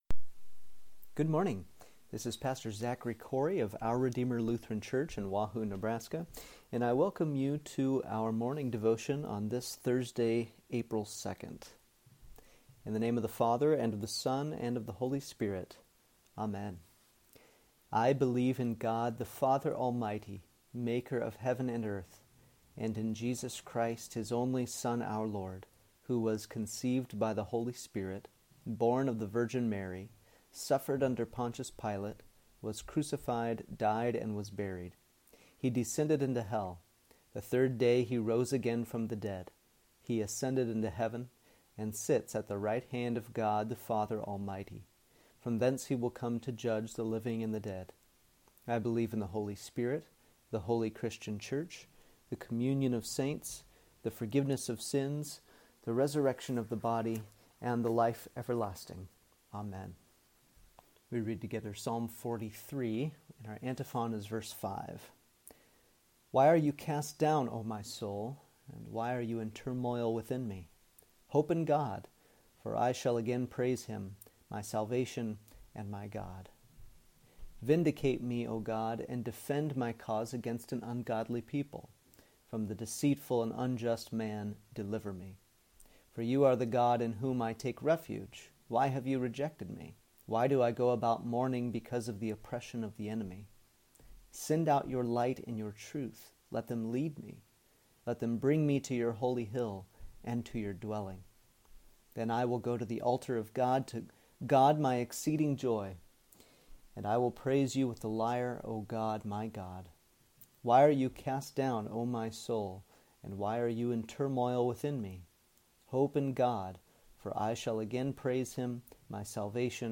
Morning Devotion for Thursday, April 2nd
Here’s Thursday’s morning devotion.